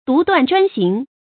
注音：ㄉㄨˊ ㄉㄨㄢˋ ㄓㄨㄢ ㄒㄧㄥˊ
獨斷專行的讀法